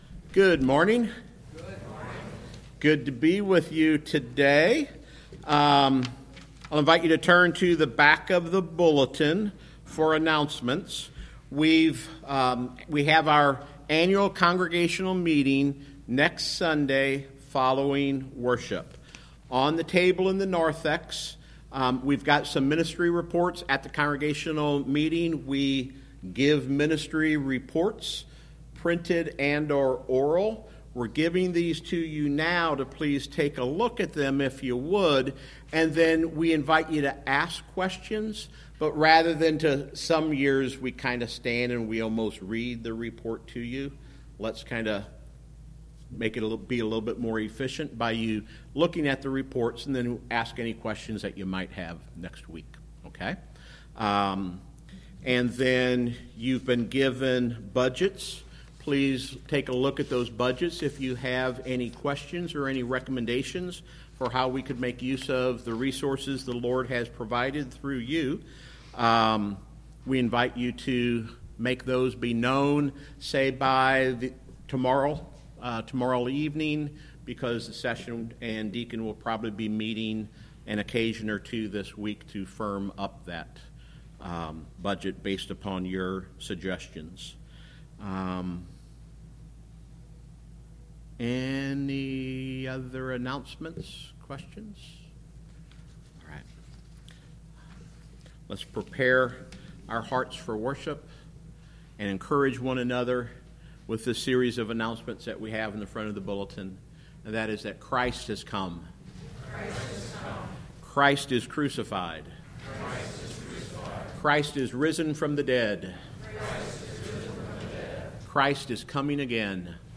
Sermon
Worship Service
Piano
Congregation Singing